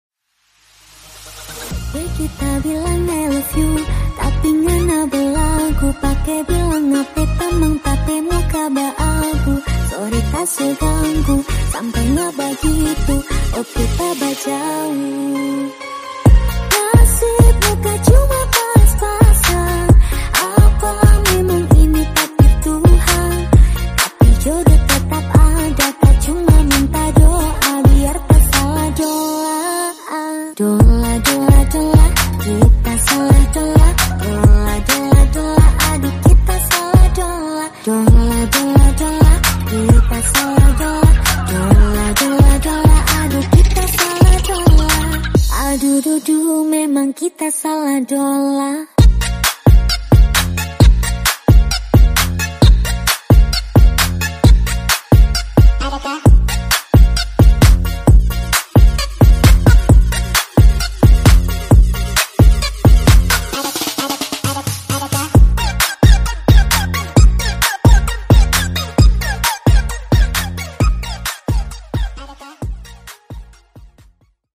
Genre: RE-DRUM Version: Clean BPM: 130 Time